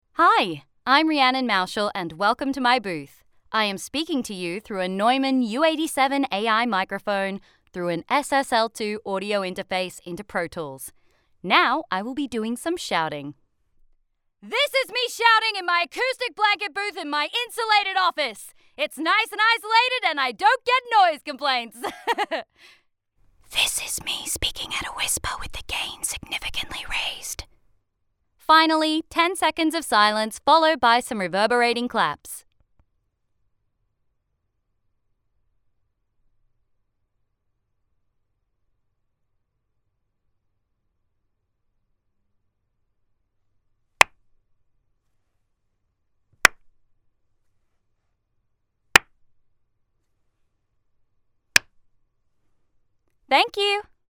Female
Australian English (Native) , American English , British English
Bright, Character, Friendly, Versatile
Australian; General American; American Southern; British RP; Cockney; West Country; Estuary; Scottish
Microphone: Neumann U 87 Ai, Rode NT1-A
Audio equipment: SSL2; PVC and acoustic blanket booth